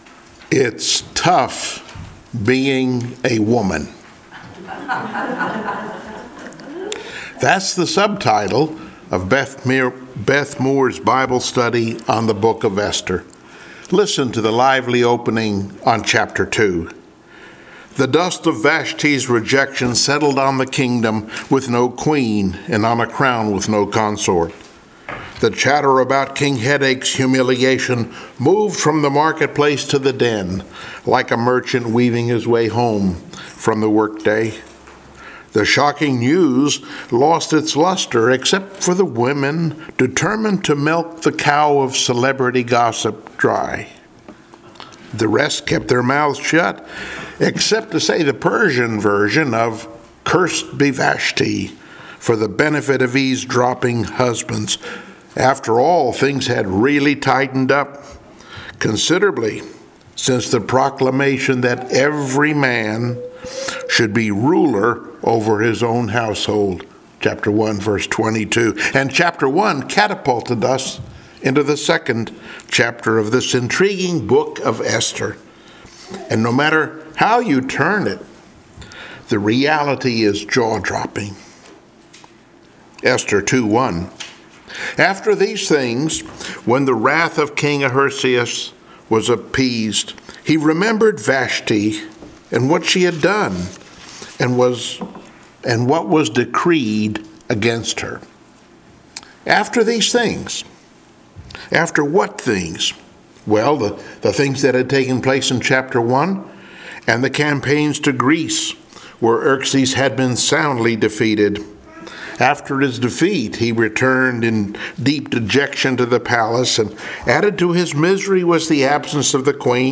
Passage: Esther2:1-20 Service Type: Sunday Morning Worship